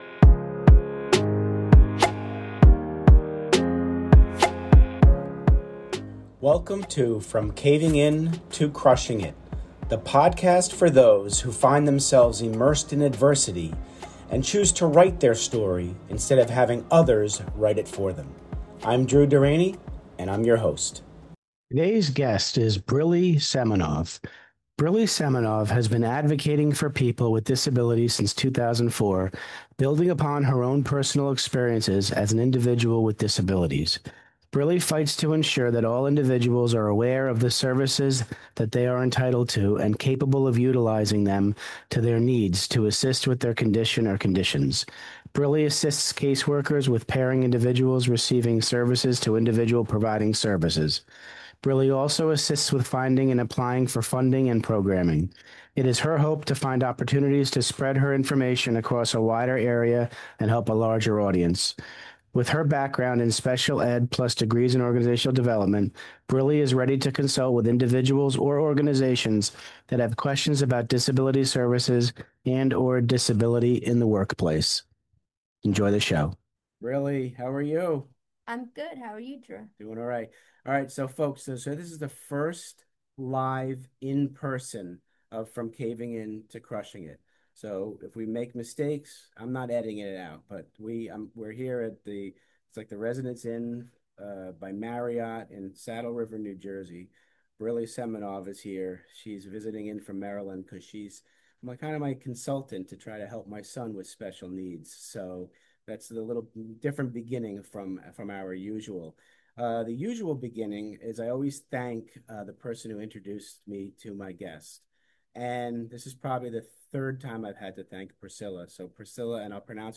The interview is held at the Residence Inn by Marriott in Saddle River, New Jersey.